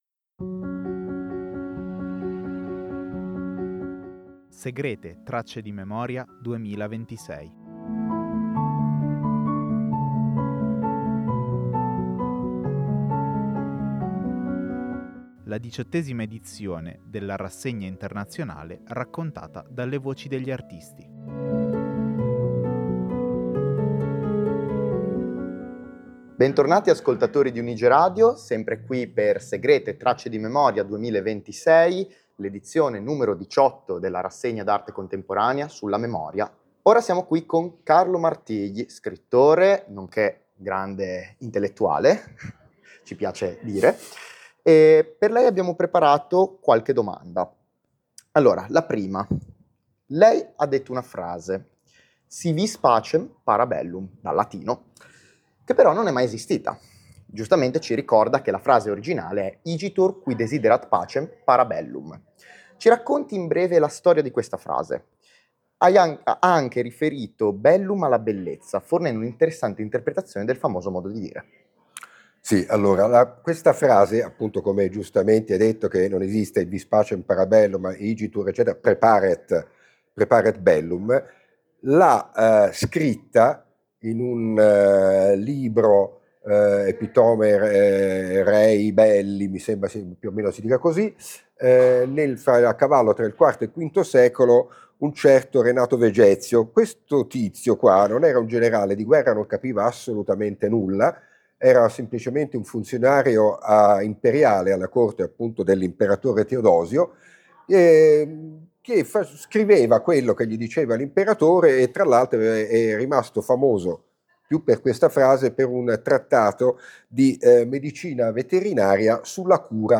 Carlo Martigli scrittore e grande intellettuale si racconta ai nostri microfoni. Intervista